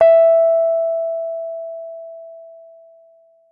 Rhodes_MK1
e4.mp3